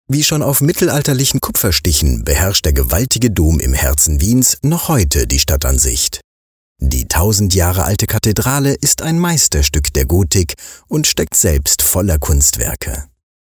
Male
Spanish (Latin American)
Audio Guides